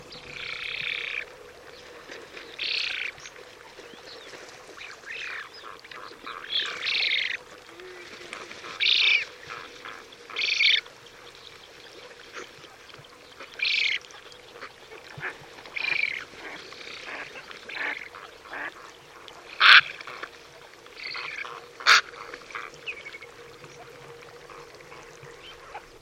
spatule-blanche.mp3